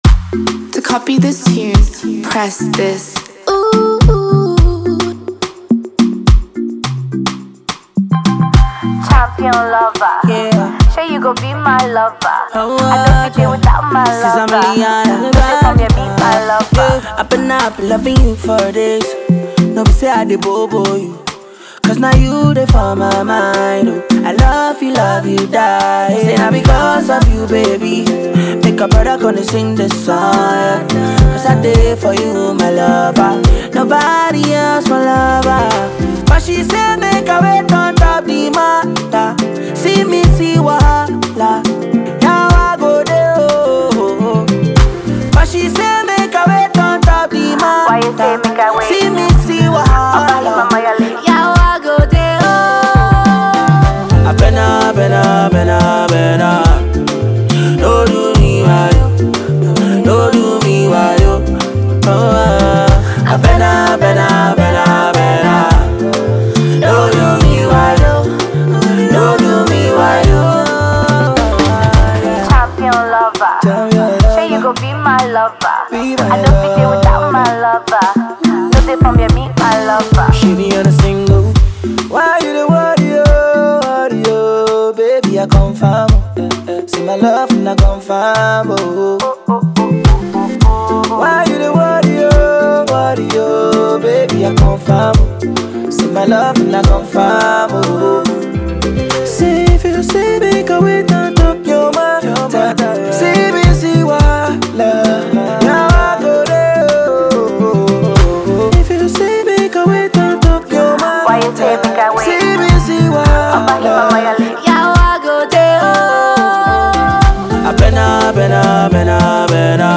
melodious record